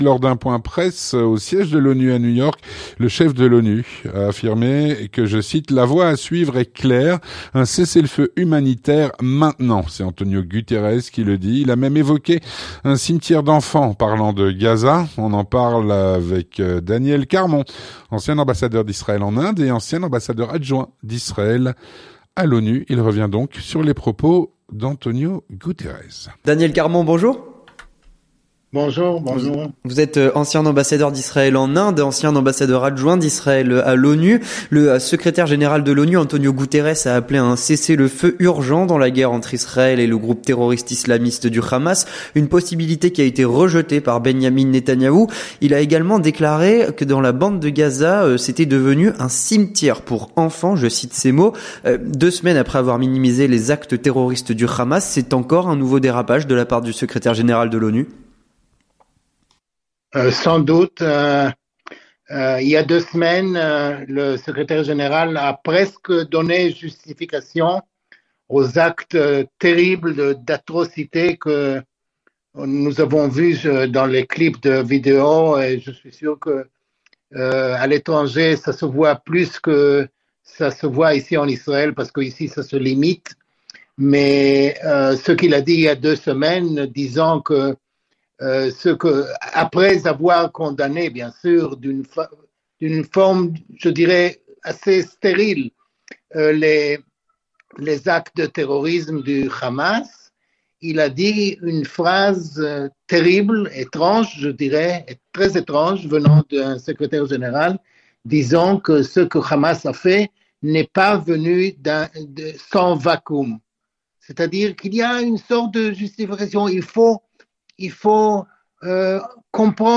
L'entretien du 18H - Décryptage des propos d'Antonio Guterres.
Avec Daniel Carmon, ancien ambassadeur d’Israël en Inde et ancien ambassadeur adjoint d’Israël à l’ONU